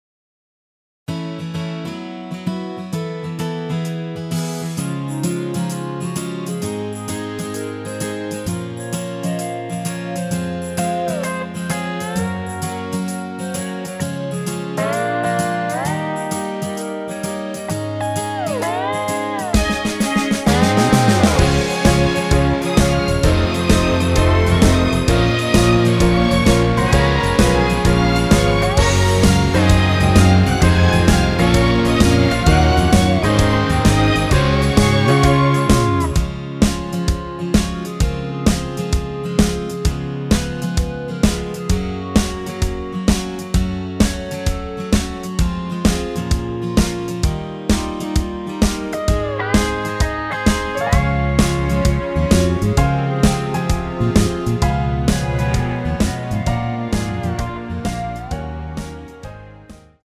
전주 없는 곡이라 전주 2마디 만들어 놓았습니다.
엔딩이 페이드 아웃이라라이브 하시기 편하게 엔딩을 만들어 놓았습니다.
Db
앞부분30초, 뒷부분30초씩 편집해서 올려 드리고 있습니다.
중간에 음이 끈어지고 다시 나오는 이유는